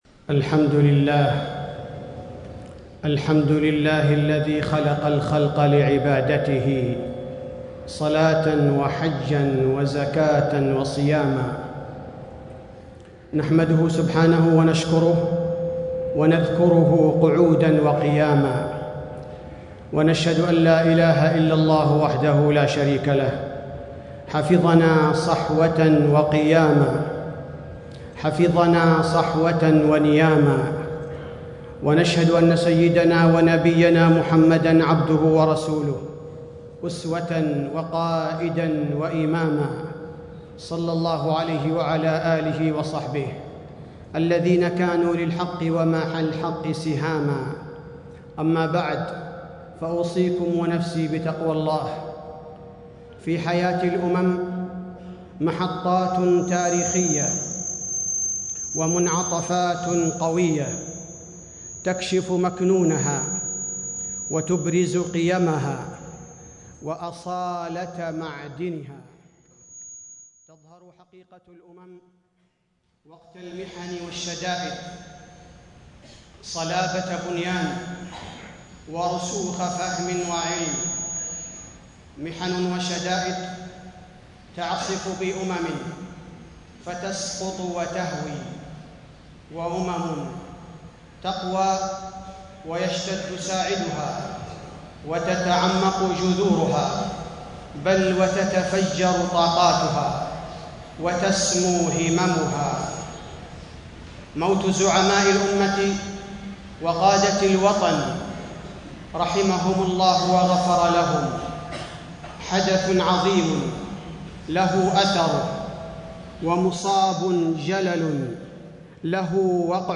تاريخ النشر ١٠ ربيع الثاني ١٤٣٦ هـ المكان: المسجد النبوي الشيخ: فضيلة الشيخ عبدالباري الثبيتي فضيلة الشيخ عبدالباري الثبيتي البيعة وأحكامها